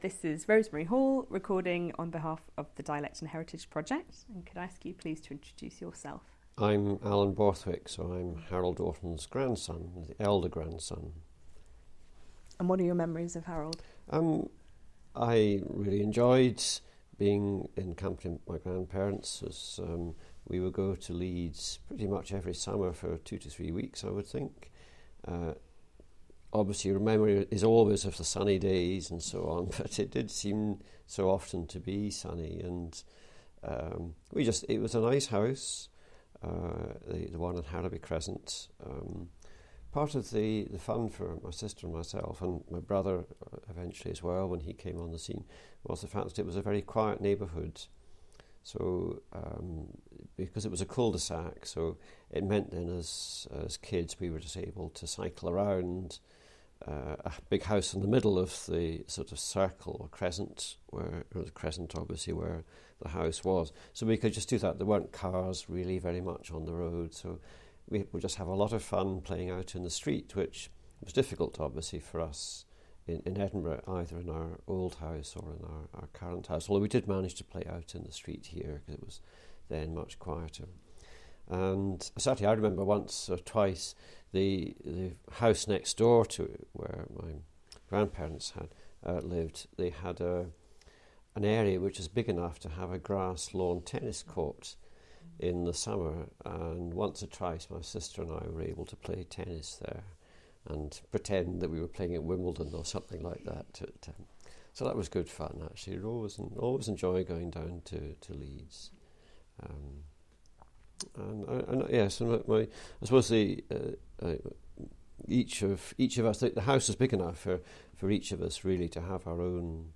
Digital recording of oral history interview conducted with named interviewee (see item title) as part of National Lottery Heritage funded, "Dialect and Heritage" Project.